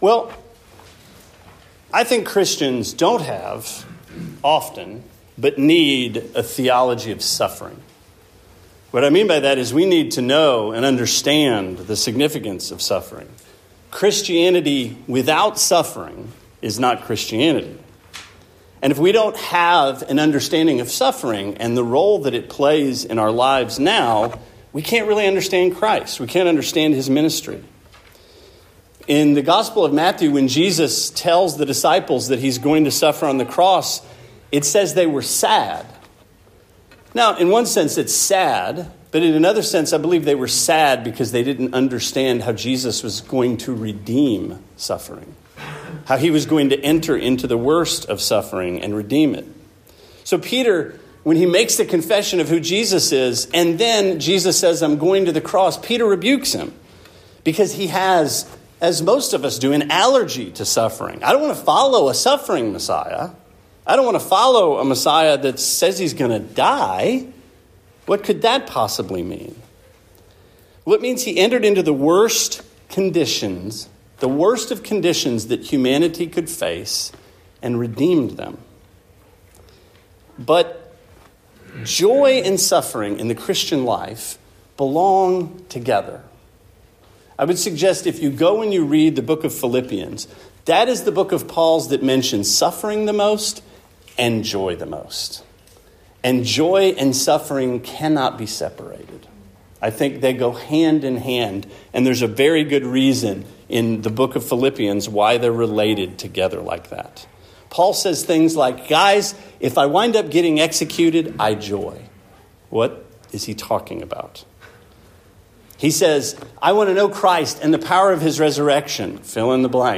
Sermon 3/12: Many Are the Afflictions of the Righteous